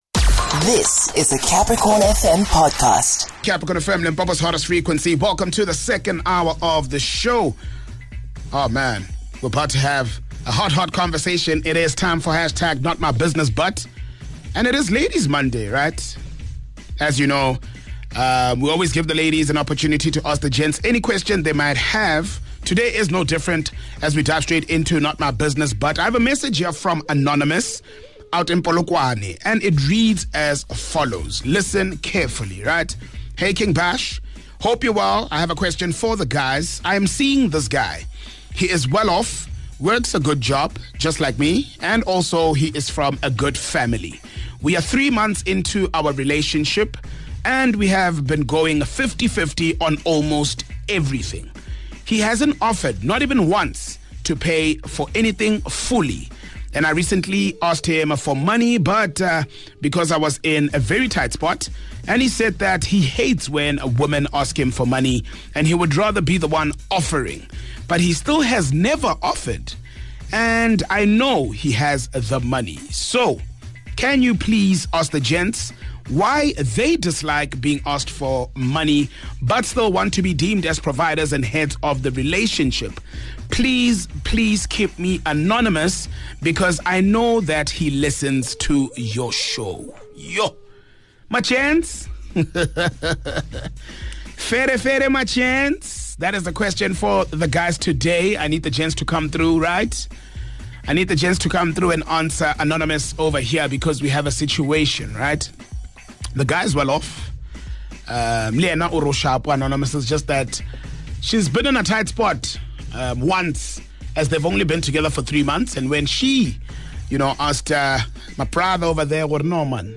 In this podcast, Anonymous wants to know why some men dislike being asked for money and prefer to offer it themselves, even though they want to be seen as providers from the start. The gents of the Capricorn Adventure show up in numbers, sharing different views as they answer her question.